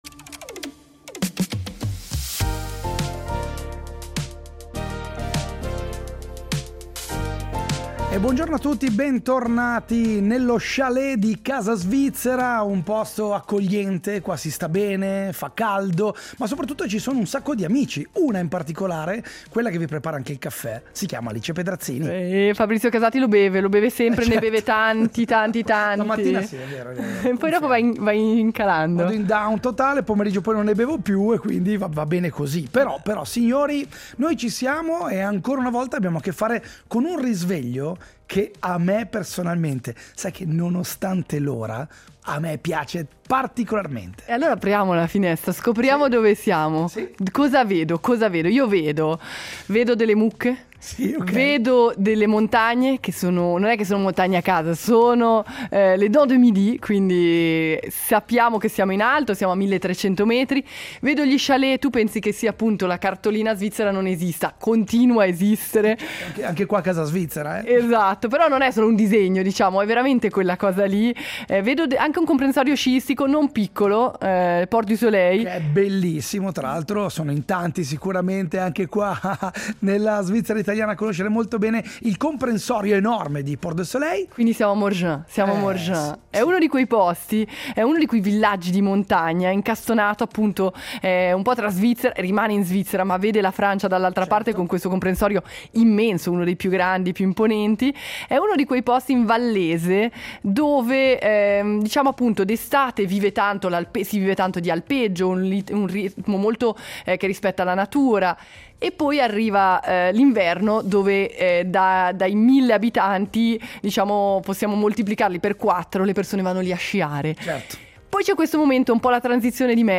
che ci parla in dialetto, con accento americano